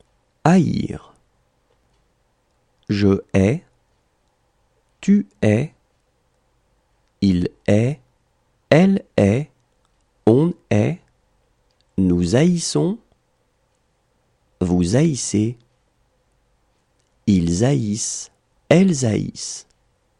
• Et pour ce verbe, pas d'apostrophe et pas de liaison ! (car c'est un "h" aspiré).